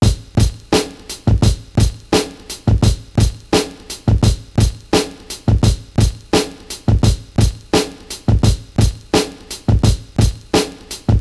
• 86 Bpm Breakbeat Sample G# Key.wav
Free drum loop - kick tuned to the G# note. Loudest frequency: 1200Hz
86-bpm-breakbeat-sample-g-sharp-key-UCU.wav